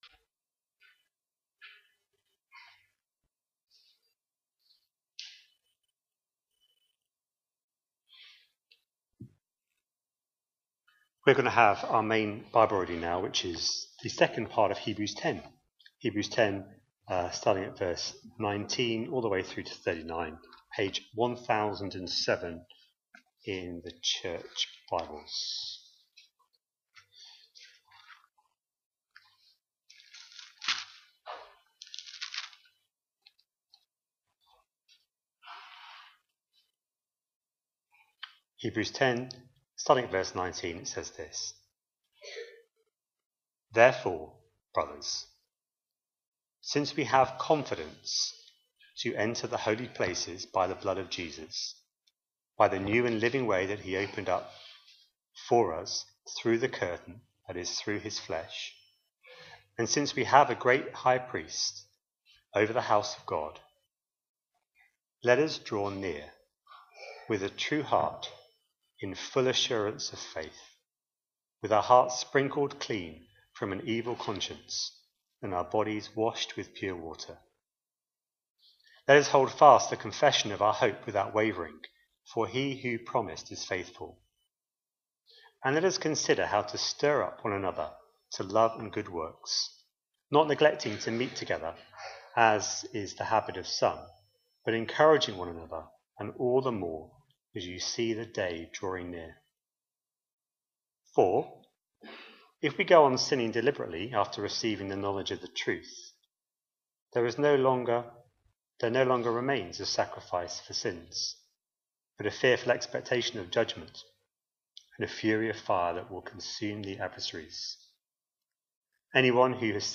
A sermon preached on 9th February, 2025, as part of our Hebrews 24/25 series.